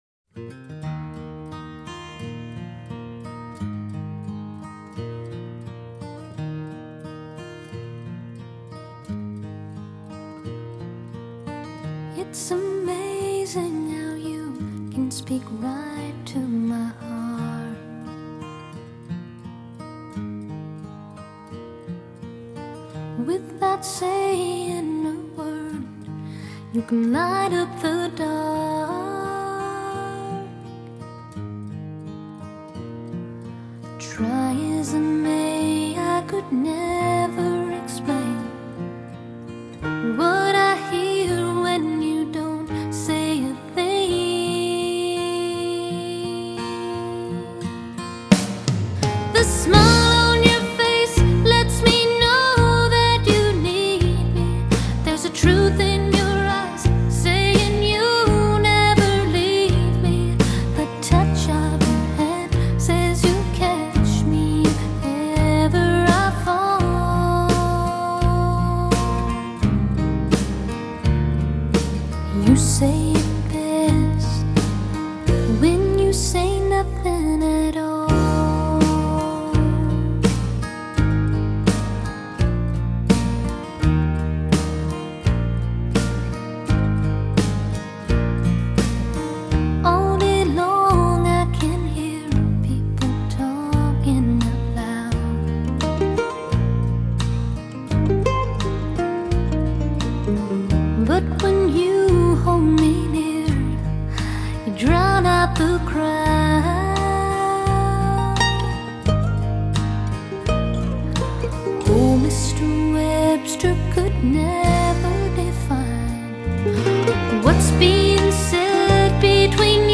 ★ 藍草音樂天后早期代表作，雙白金暢銷專輯！
★ 全音音樂網站五顆星無條件推薦，《滾石》雜誌四星高評，歌曲首首動聽，錄音鮮活絲滑！